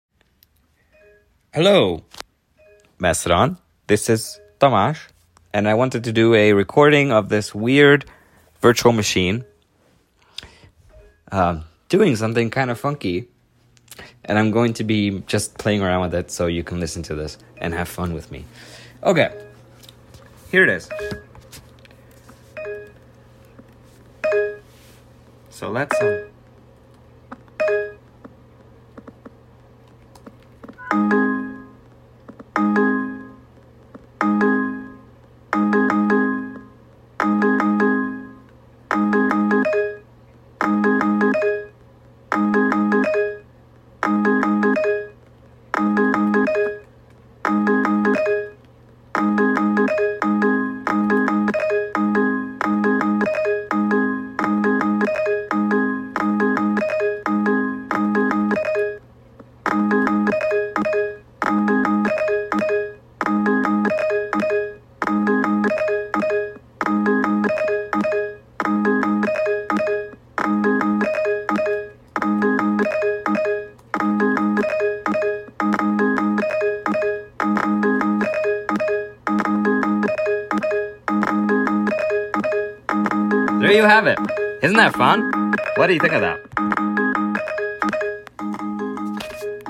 Something fun with NVDA remote! Listen to this nice beat it can make.